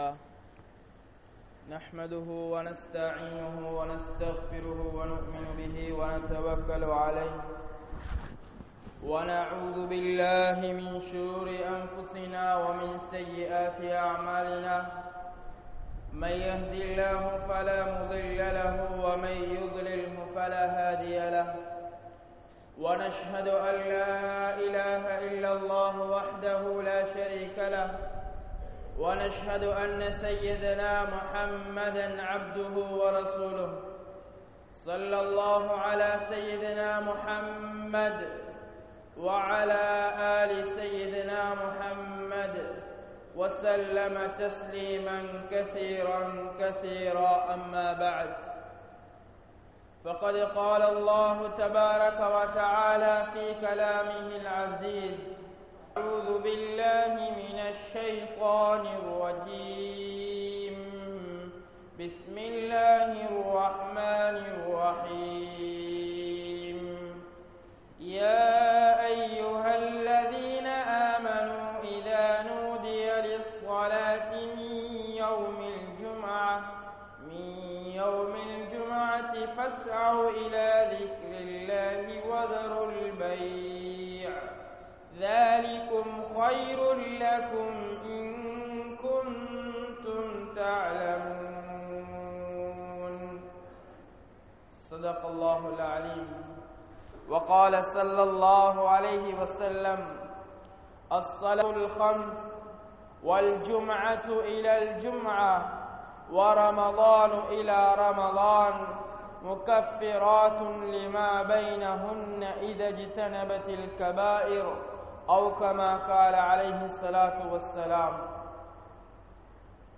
Virtues Of Jumuah | Audio Bayans | All Ceylon Muslim Youth Community | Addalaichenai